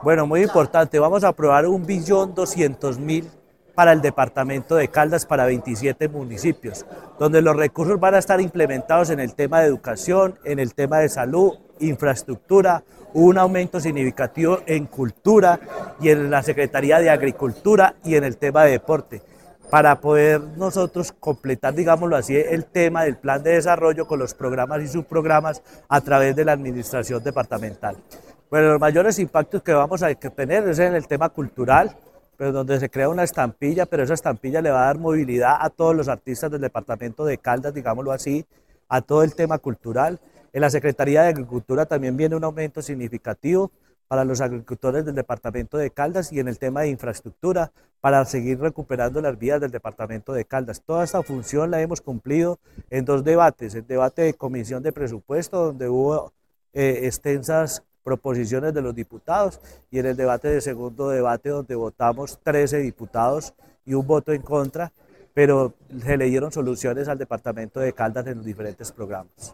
Oscar Alonso Vargas, diputado de Caldas